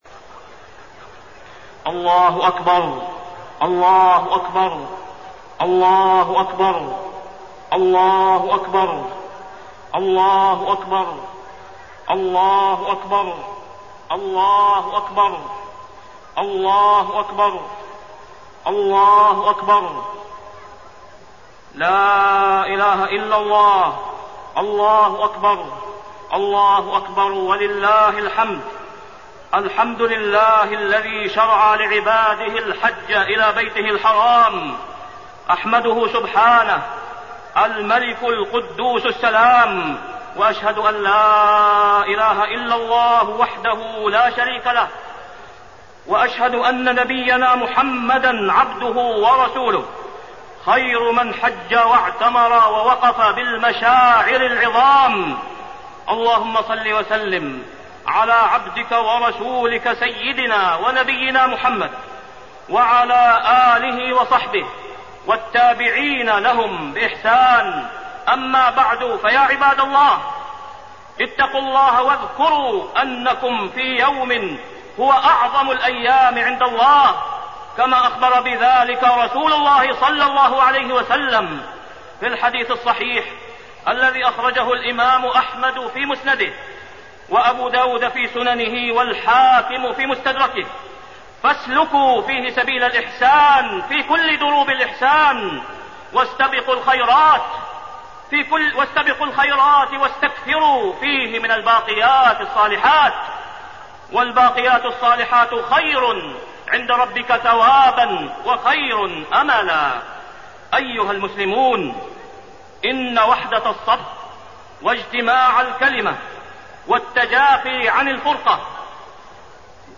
تاريخ النشر ١٠ ذو الحجة ١٤٢٣ هـ المكان: المسجد الحرام الشيخ: فضيلة الشيخ د. أسامة بن عبدالله خياط فضيلة الشيخ د. أسامة بن عبدالله خياط وحدة الصف